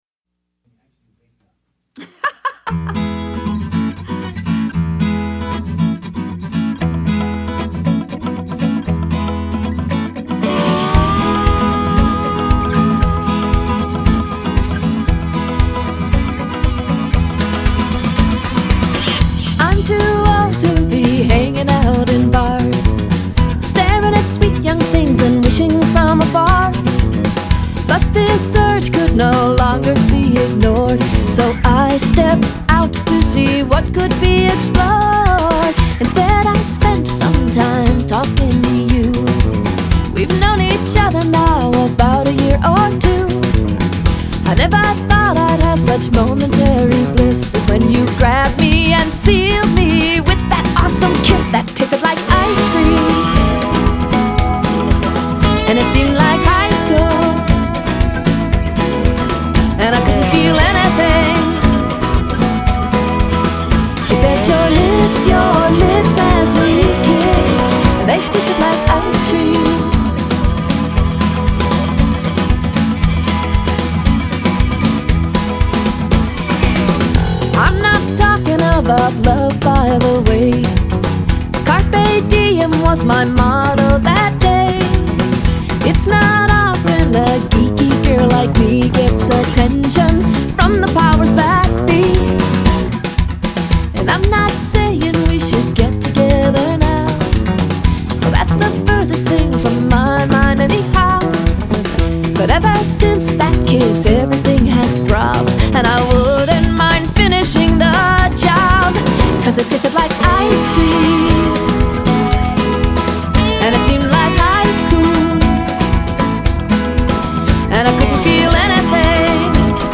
Uptempo Fm vx, Full band